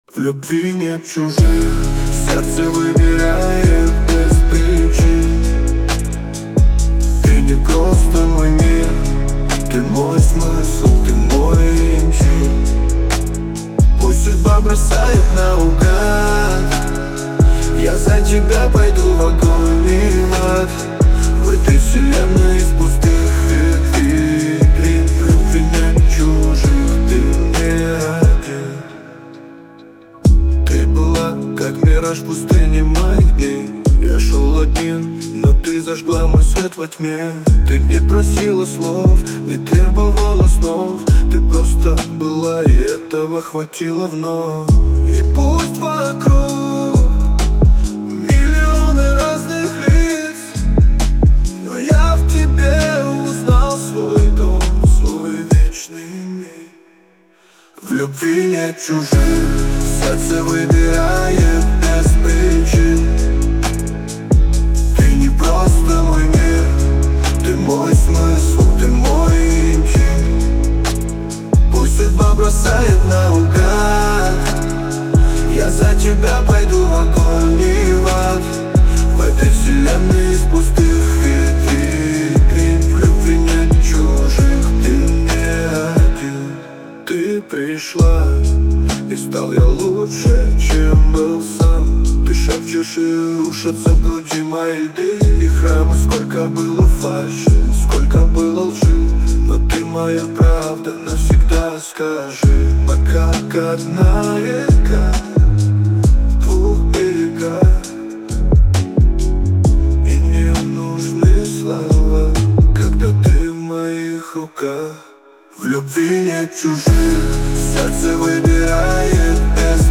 Жанр: Поп, Танцевальный